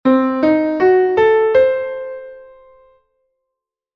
The musical notation 🎼 for our constructed scale (C - E♭ - G♭ - A - C) would look like this:
The minor third scale sounds a little dark.
Because the distance between the octaves is short (i.e. from C to C), the scale sounds more dissonant and solemn.
minor-third-scale.mp3